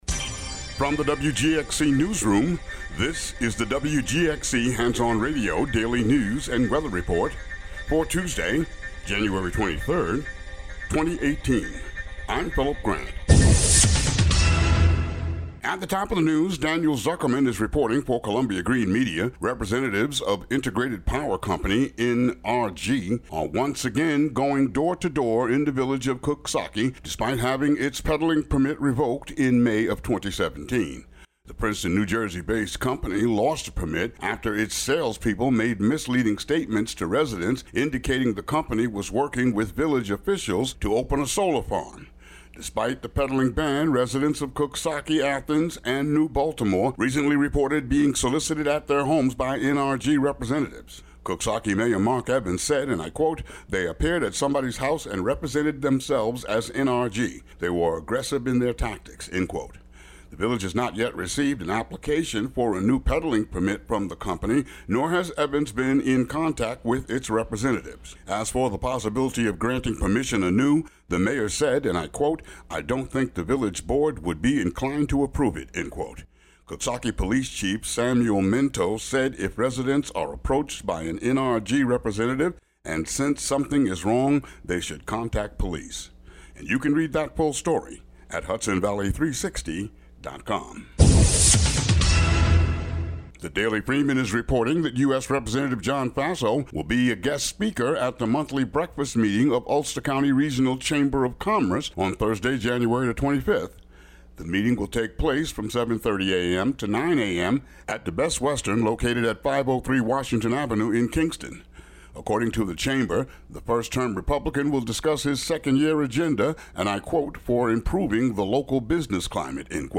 Local news for Jan. 23, 2018.